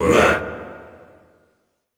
Index of /90_sSampleCDs/Best Service - Extended Classical Choir/Partition I/DEEP SHOUTS
DEEP UAE 1-R.wav